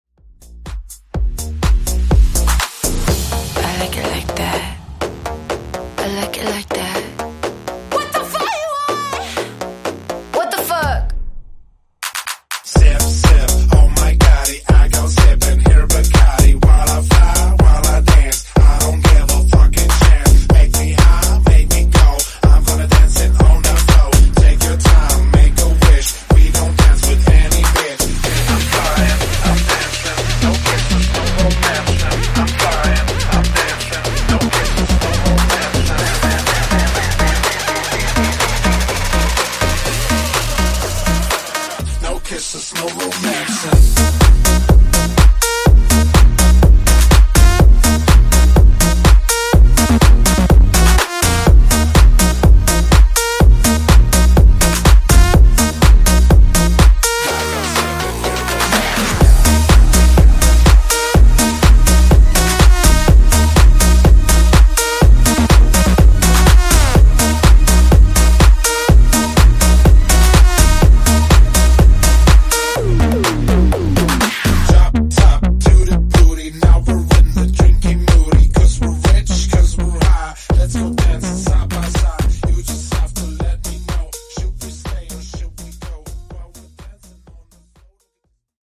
Version: Clean BPM: 96 Time